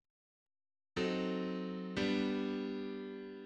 Summary [ edit ] Description Secondary leading-tone chord.mid English: Secondary leading-tone chord: viio7/V - V in C major.
Secondary_leading-tone_chord.mid.mp3